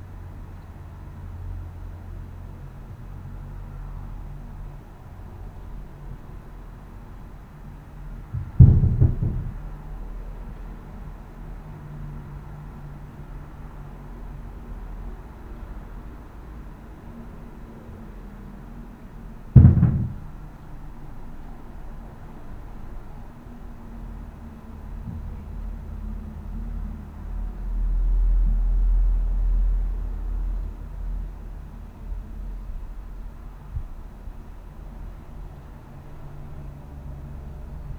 Das Logo am Kopf meiner Webseite ist der Graph einer akustischen Probe das ich in meiner Wohnung aufgenommen habe um die Geräusche die in der oberen Etage erzeugt werden zu protokollieren, dies ist die dazugehörige, aufgenommene Probe als
Die akustische Probe wird vielleicht zur Vermutung führen, dass es hier um Holzbau geht, dem ist aber nicht so. Es ist eine Mehrgeschosswohnung mit Baujahr 1961, mit Decken aus Stahlbeton und Ziegelwänden.